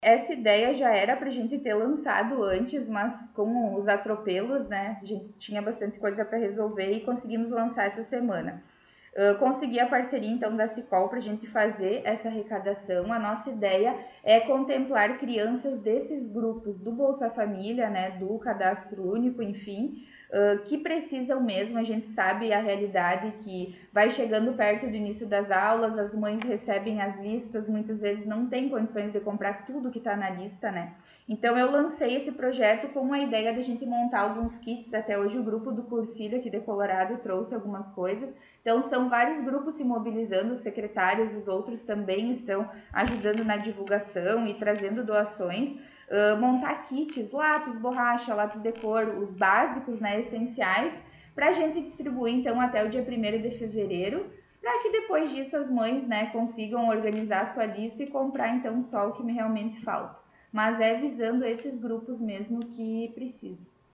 Secretária de Ação Social, Habitação e Saneamento concedeu entrevista